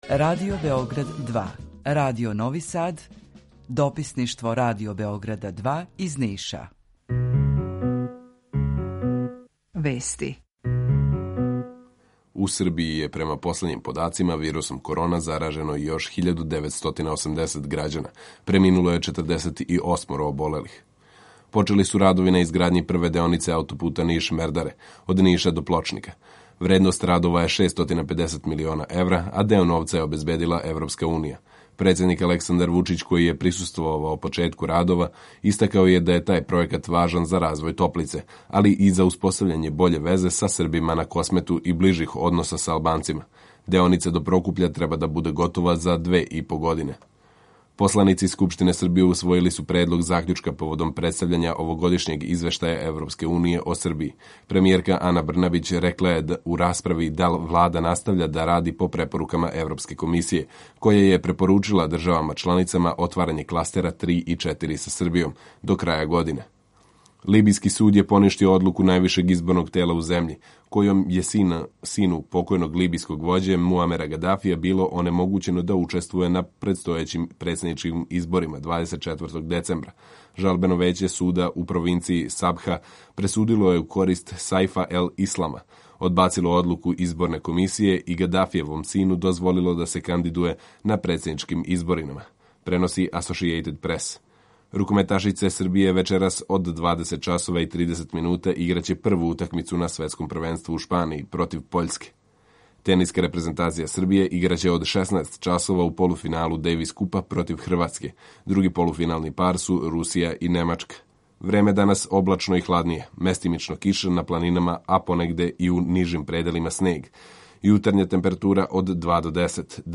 Емисију реализујемо заједно са студијом Радија Републике Српске у Бањалуци и Радија Новог Сада
Јутарњи програм из три студија
У два сата, ту је и добра музика, другачија у односу на остале радио-станице.